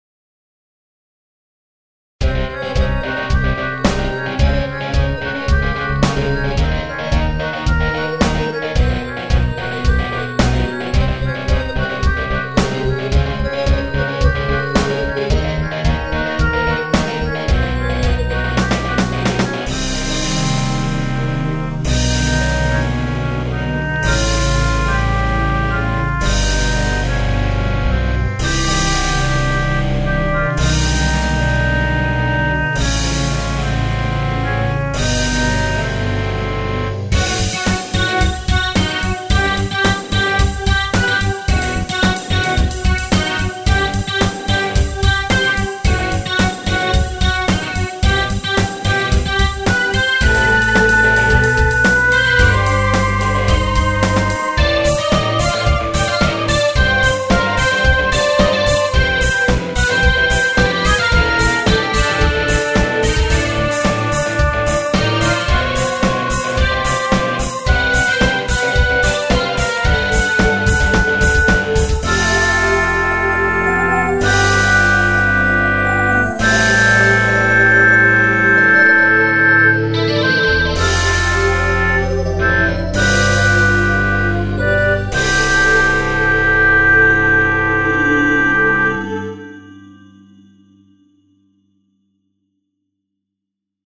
BGM
ショートロック暗い